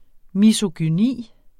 Udtale [ misogyˈniˀ ]